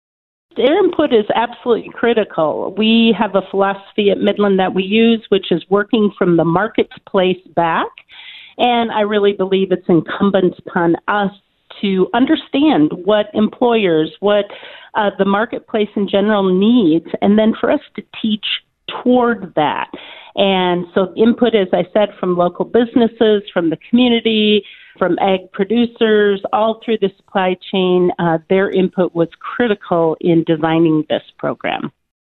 was a guest on the KHUB Morning Show on Thursday